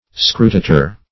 Search Result for " scrutator" : The Collaborative International Dictionary of English v.0.48: Scrutator \Scru*ta"tor\, n. [L.]
scrutator.mp3